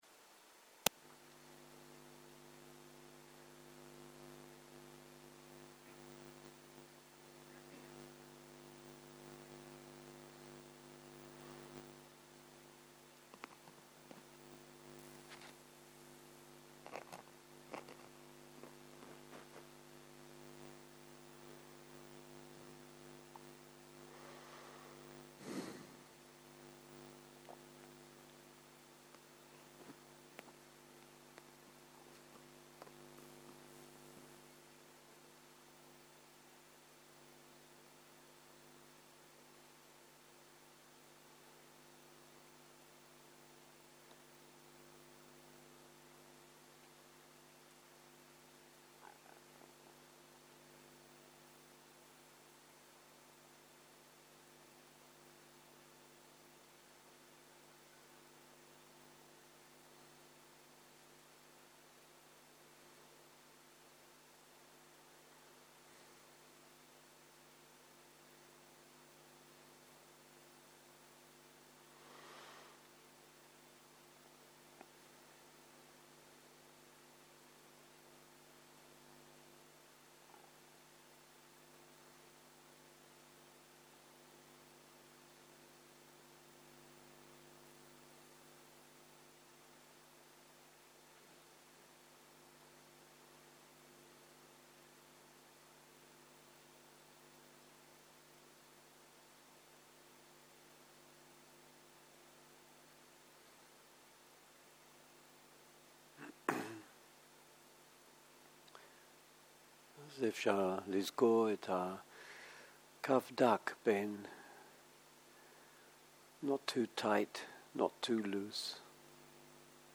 מדיטציה מונחית - תנוחה וודאנה - צהריים
סוג ההקלטה: מדיטציה מונחית